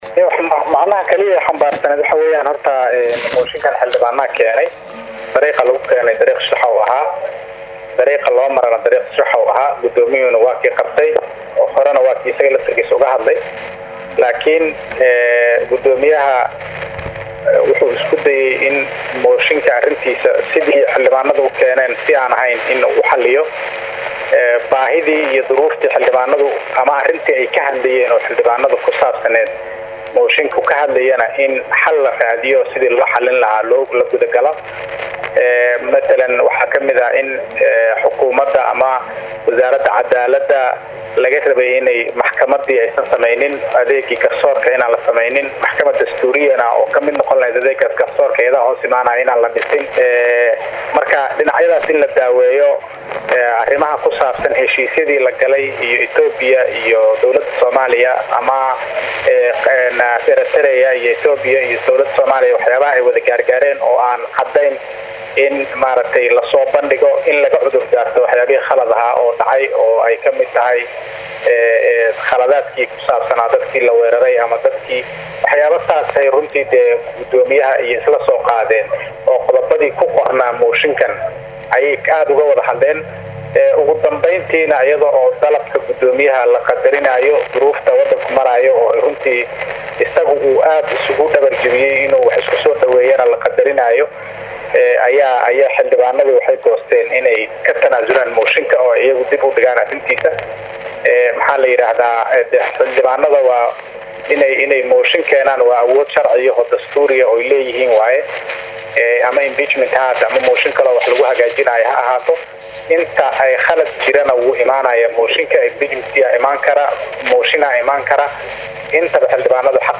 Xildhibaan C/xakiin Macalin oo kamid ah xildhibaanadii gudbiyay Mooshinka ayaa wareysi uu bixinayay wuxuu si cad u sheegay inay iyaga go’aansadeen inay ka laabtaan mooshinkii ay horey u keeneen.
Wareysi-Xildhiban-Cabdi-Xakiin-Macalin-Axmed-.mp3